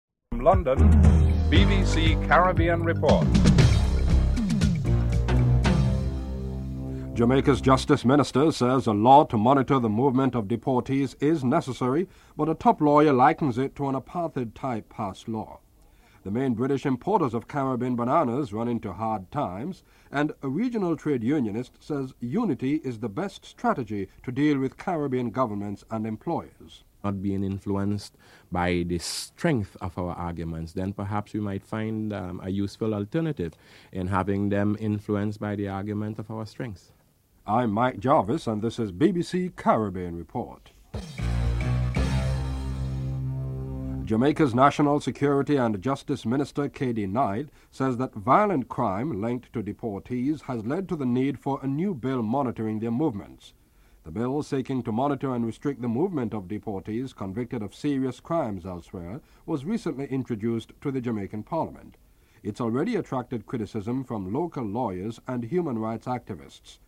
Headlines